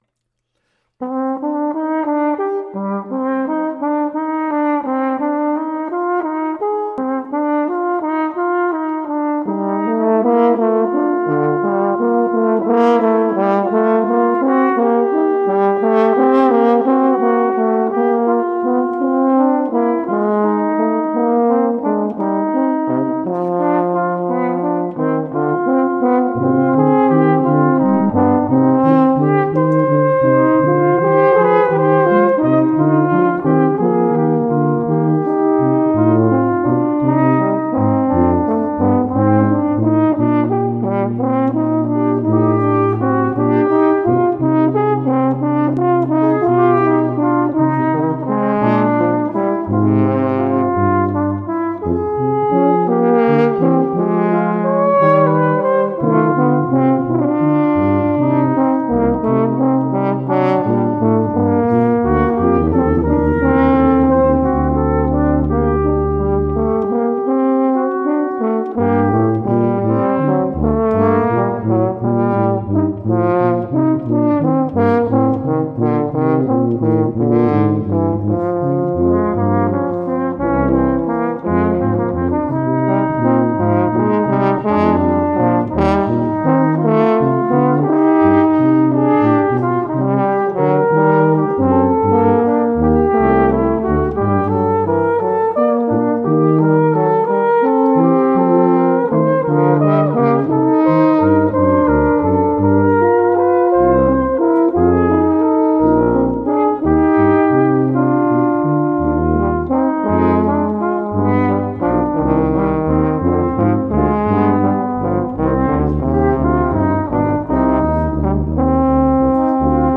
Barocke Kirchenmusik für Blechblasinstrumente
Flügelhorn, F-B-Horn, B-Tuba [5:08]